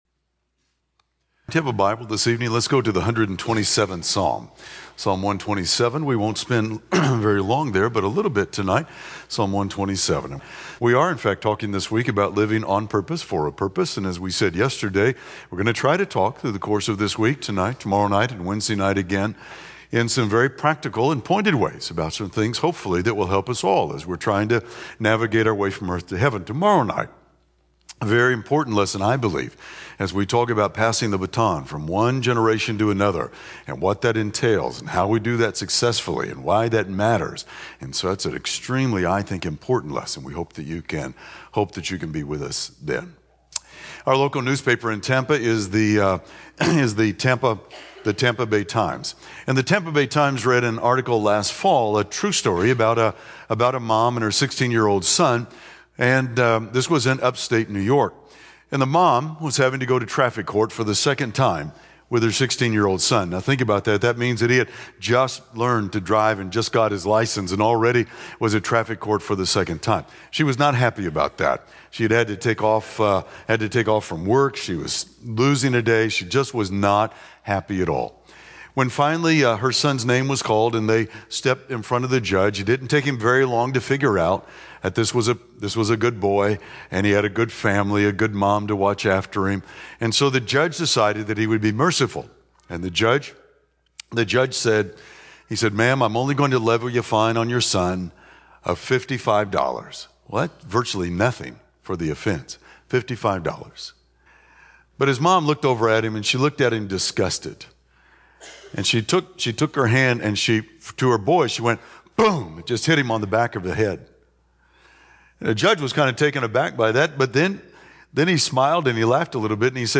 Service: Mon PM Type: Sermon